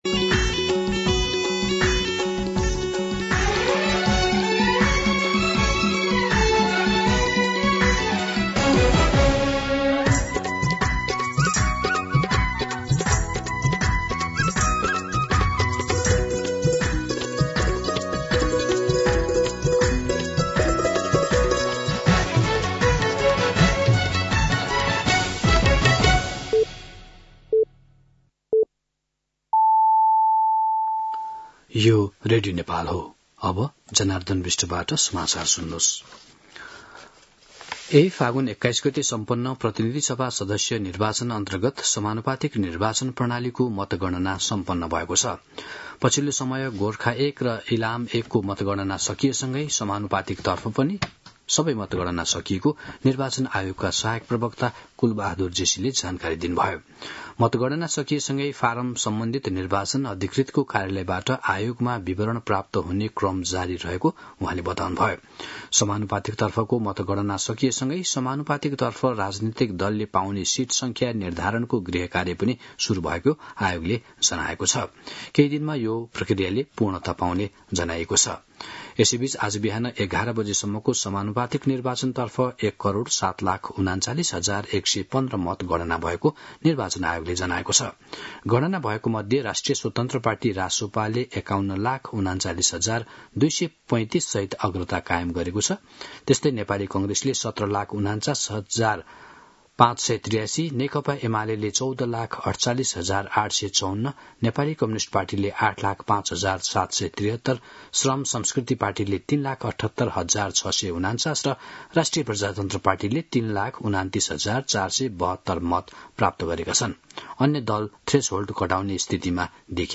मध्यान्ह १२ बजेको नेपाली समाचार : २७ फागुन , २०८२
12pm-News-27.mp3